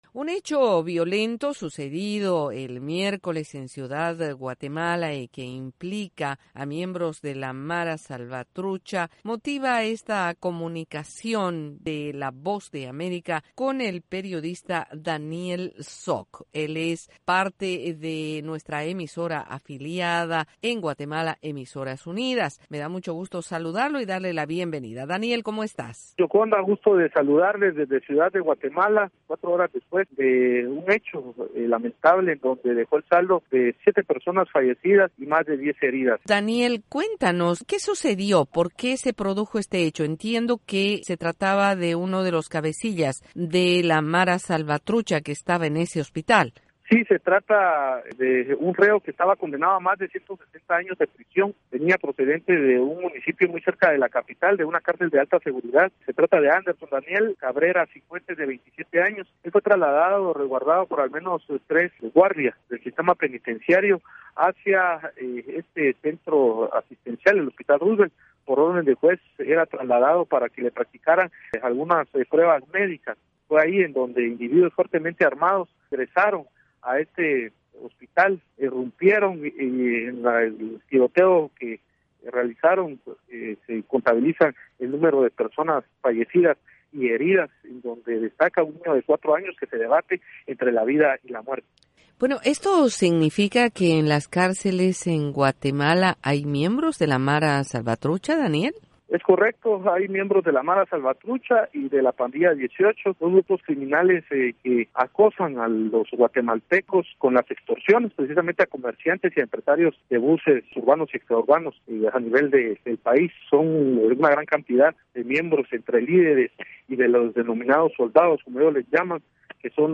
relató en una entrevista los detalles del sangriento suceso.